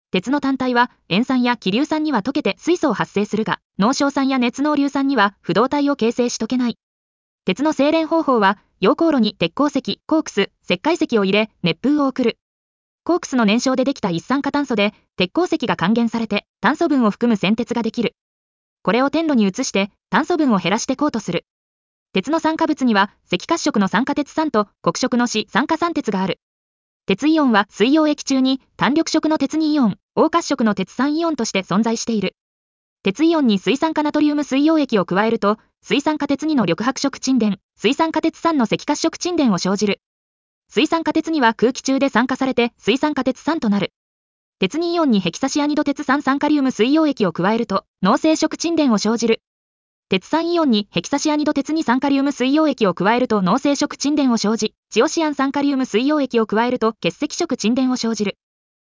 • 耳たこ音読では音声ファイルを再生して要点を音読します。通学時間などのスキマ学習に最適です。
ナレーション 音読さん